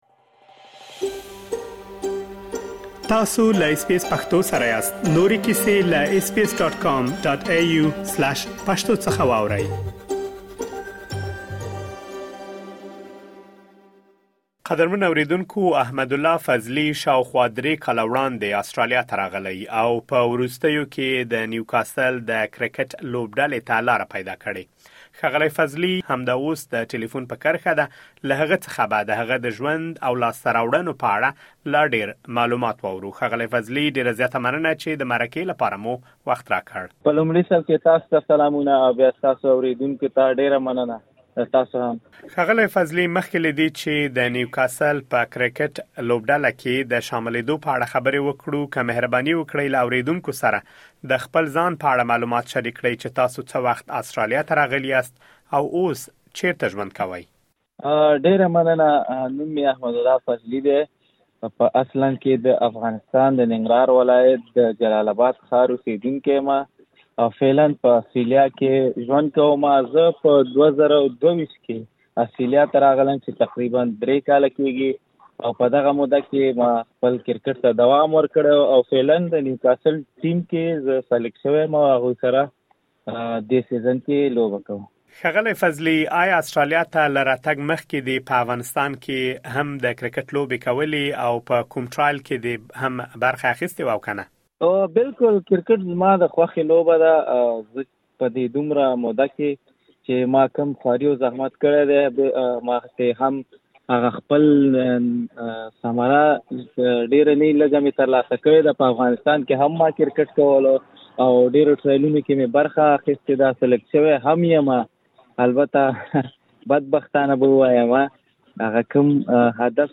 لا دېر معلومات په ترسره شوې مرکې کې اورېډلی مرکه مرکه دلته اورېدلی شئ: 08:03 زموږ د فېسبوک پاڼې له لارې د مرکو او راپورونه اورېدولو ته دوام ورکړئ.